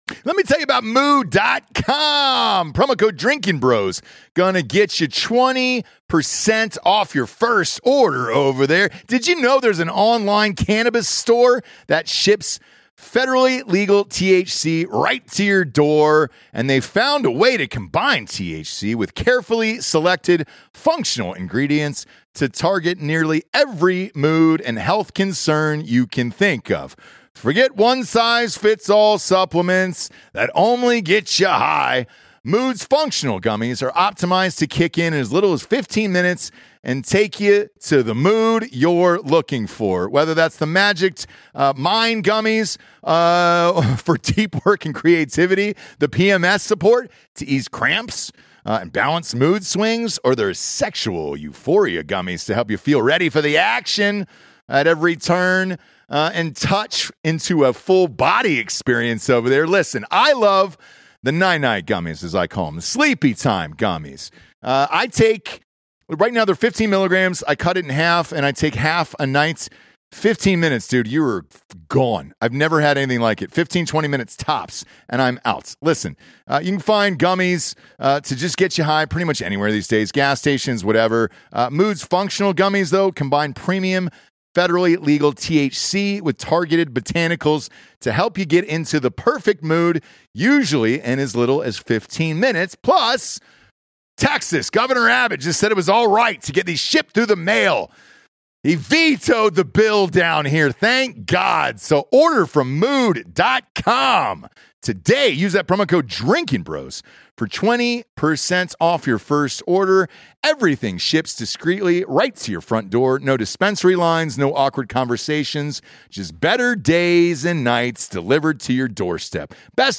Episode 819 - Special Guest Theoretical Physicist Michio Kaku